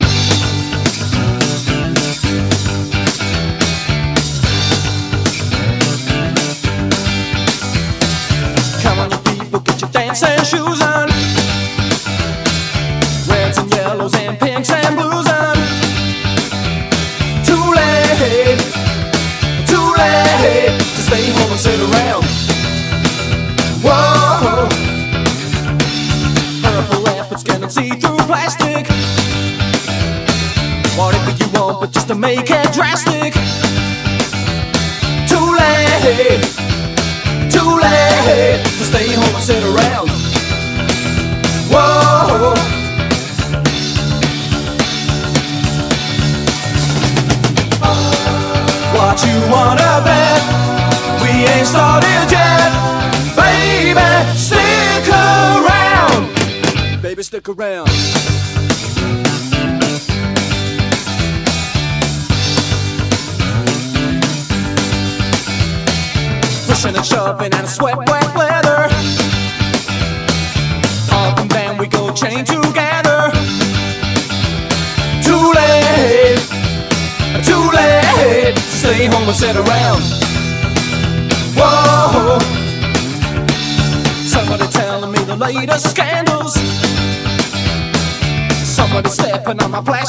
ROCK / 80'S/NEW WAVE. / NEW WAVE / NEO SKA / 80'S
お得意のパワーポップなトラックはもちろん、スカやレゲエ等も大胆に取り入れた3RDアルバム。